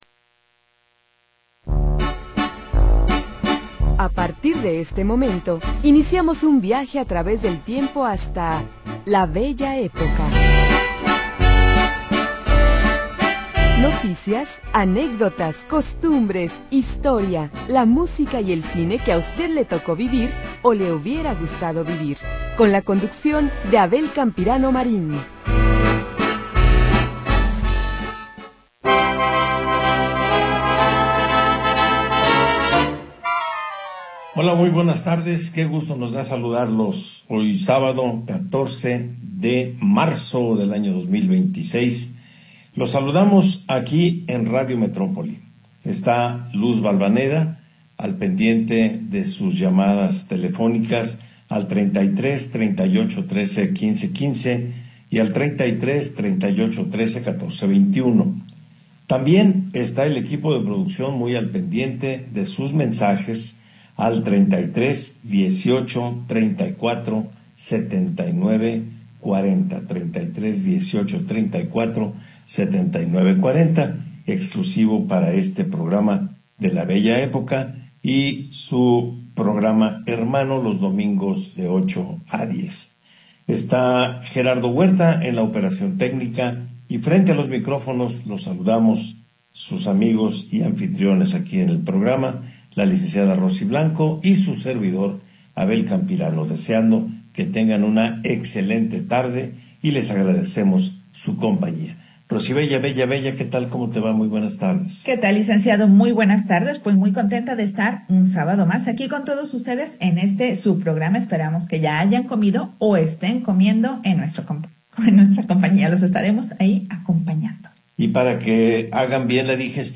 Programa transmitido el 14 de Marzo de 2026.